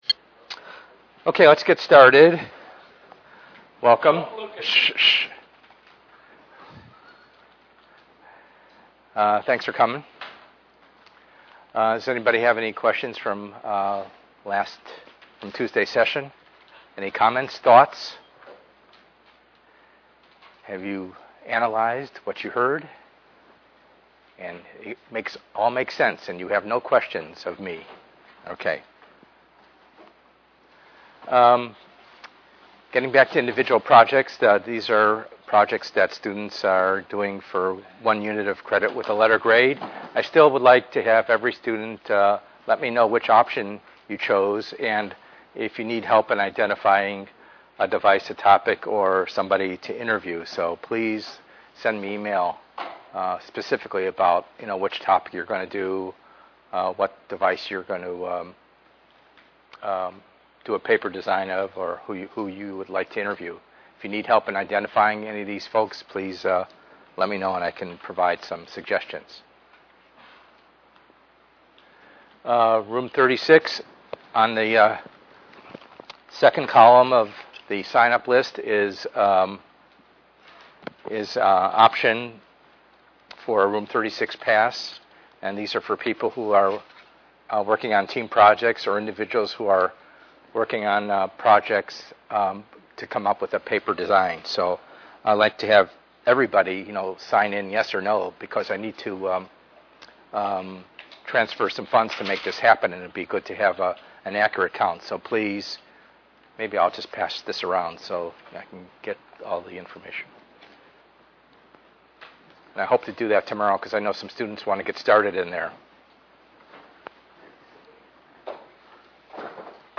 ENGR110/210: Perspectives in Assistive Technology - Lecture 4b